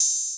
Southside Open Hatz (10).wav